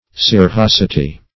Search Result for " scirrhosity" : The Collaborative International Dictionary of English v.0.48: Scirrhosity \Scir*rhos"i*ty\ (sk[i^]r*r[o^]s"[i^]*t[y^]), n. (Med.)
scirrhosity.mp3